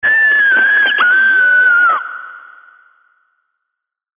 L – LISTENER SCREAM – A
L-LISTENER-SCREAM-A.mp3